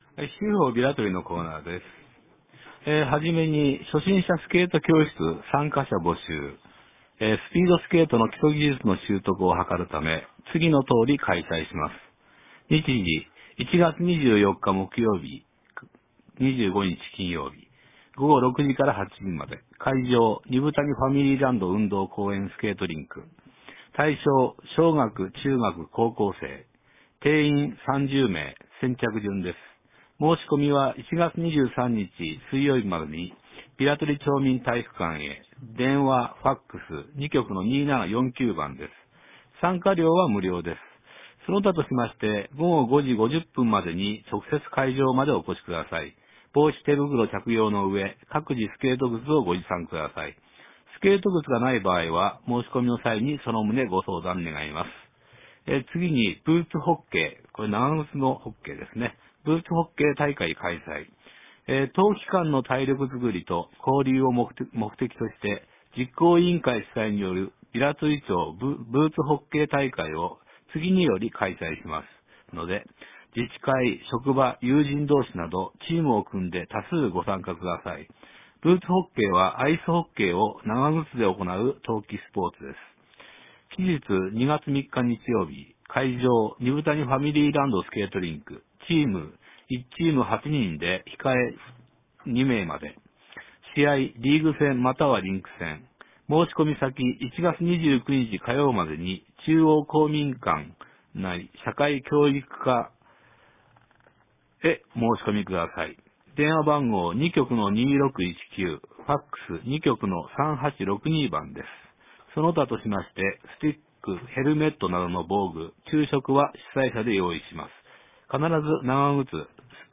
聞き手